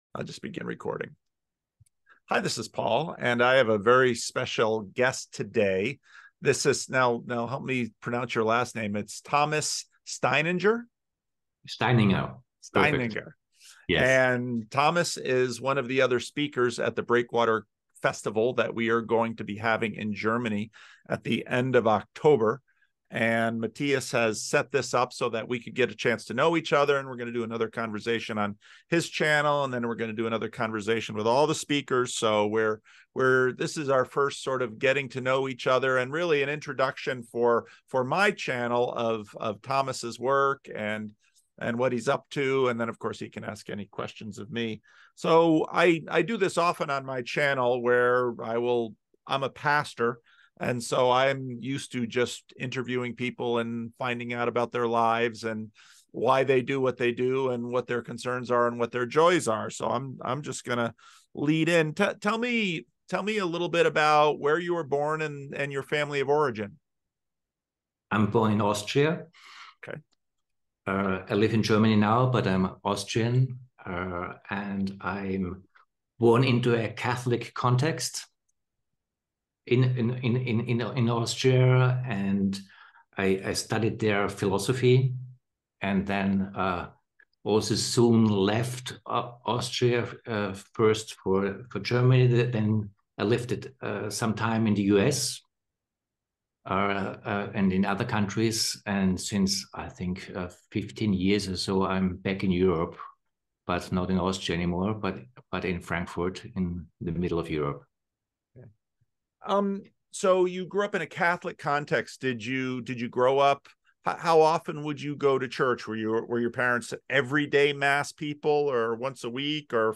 And we start with a special, longer feature: